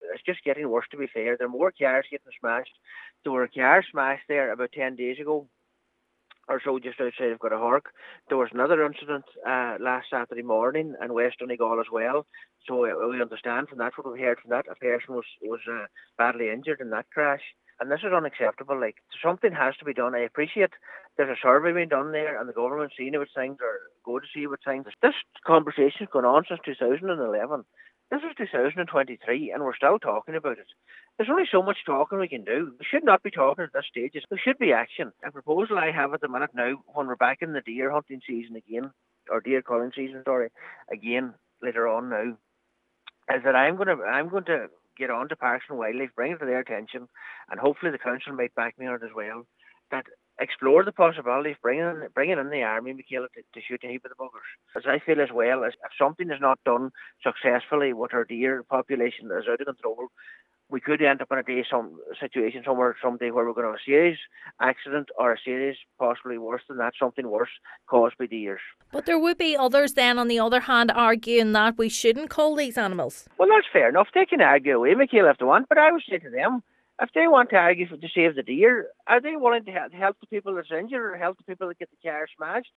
A national consultation has been carried out, and Cllr McClafferty says it’s now past time for talking.